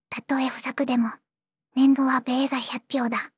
voicevox-voice-corpus
voicevox-voice-corpus / ROHAN-corpus /ずんだもん_ヒソヒソ /ROHAN4600_0045.wav